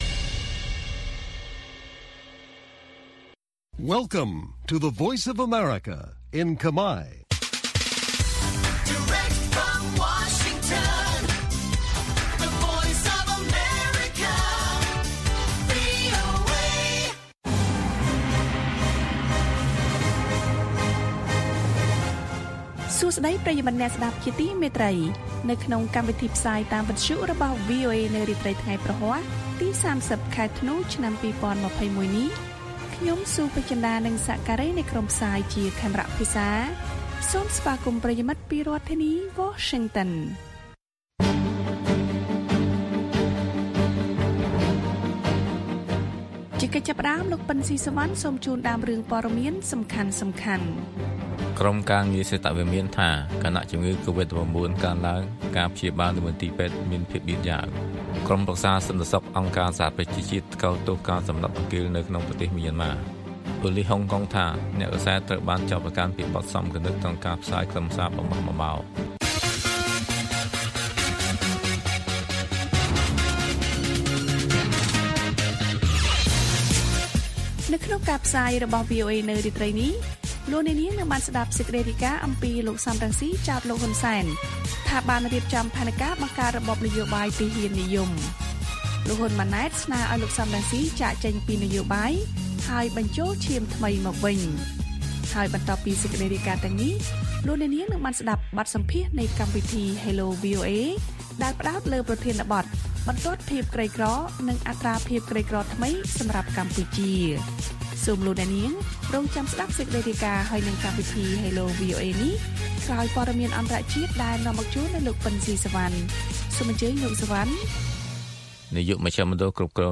ព័ត៌មានពេលរាត្រី៖ ៣០ ធ្នូ ២០២១